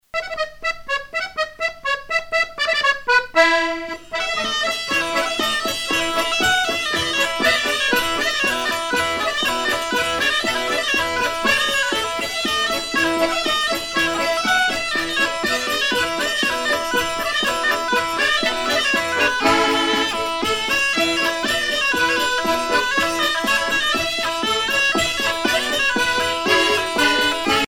danse : marche
Pièce musicale éditée